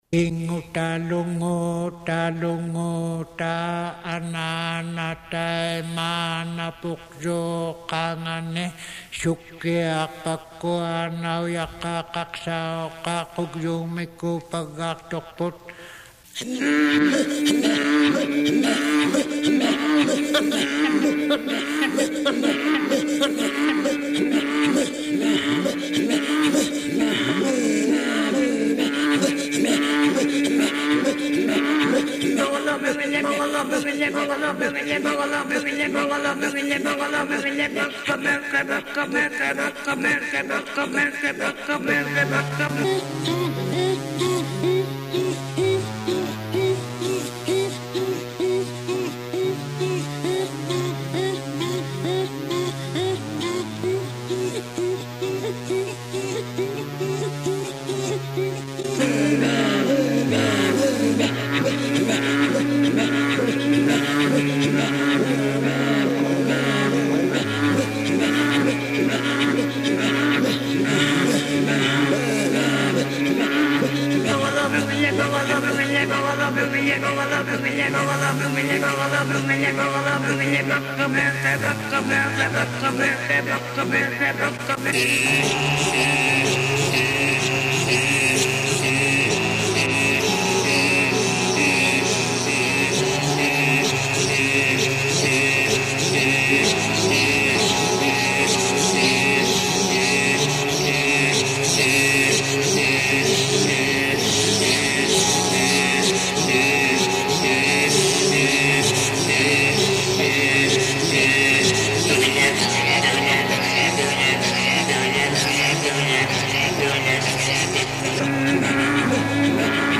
Throat Singers
hymne-inuit.mp3